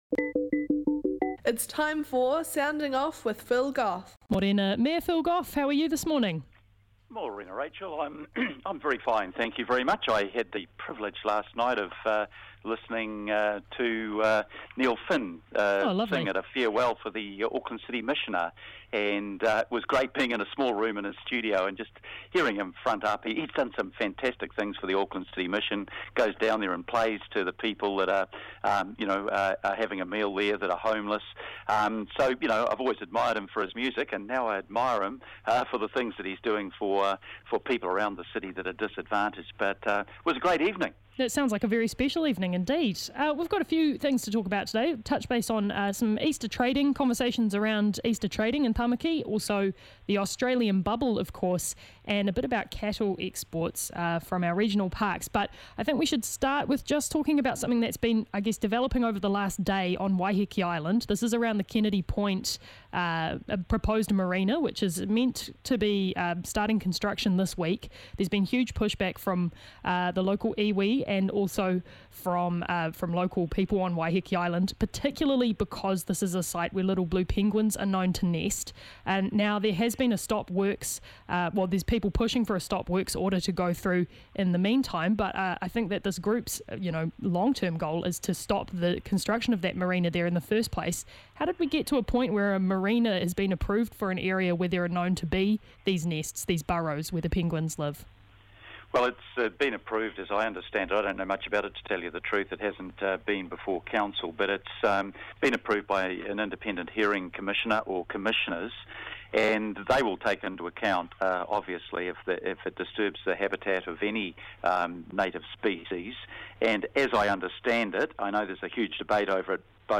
Our weekly catch up with the His Worship the Mayor of Auckland, the Honourable Phil Goff.